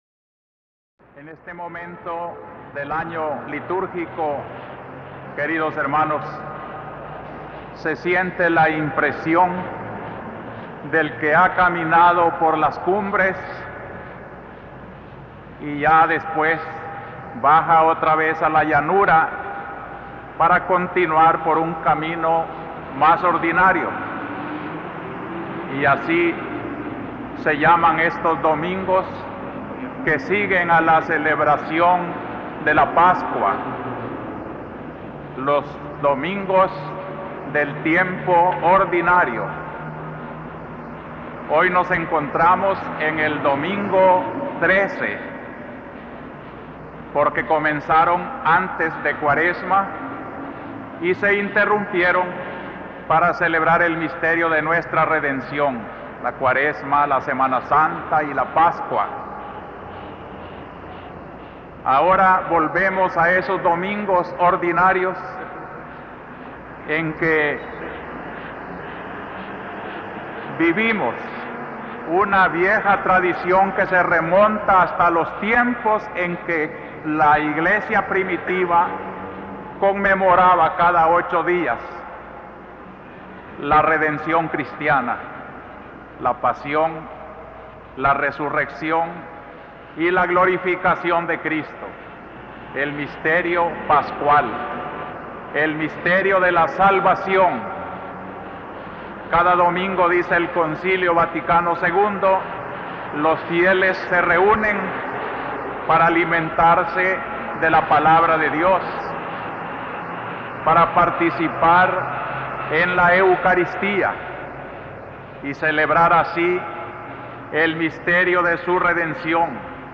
HOMILIA 1 JULIO 1979 (a).mp3